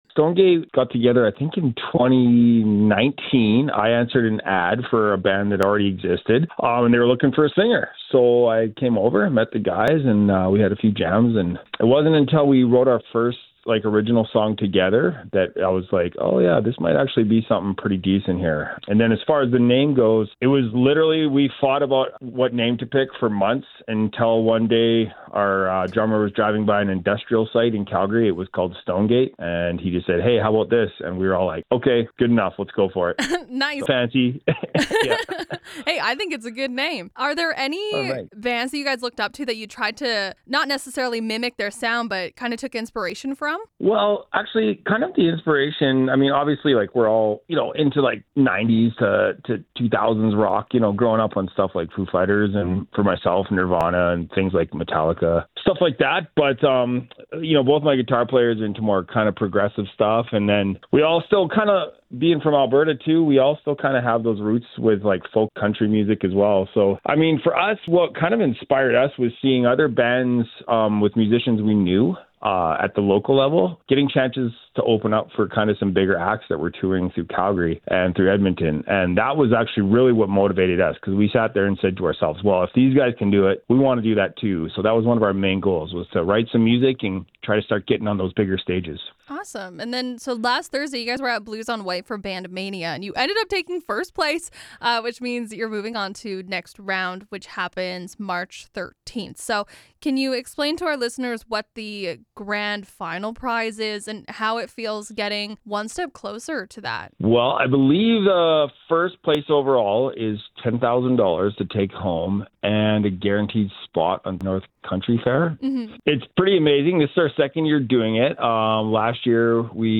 stonegate-normal-interview.mp3